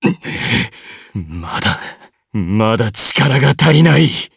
DarkKasel-Vox_Dead_jp.wav